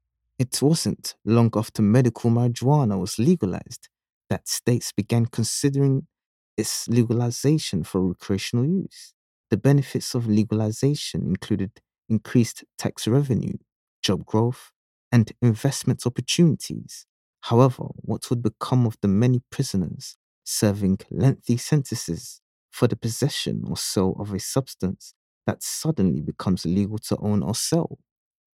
Voice Over Narration, Talent Artists & Actors
English (Caribbean)
Yng Adult (18-29) | Adult (30-50)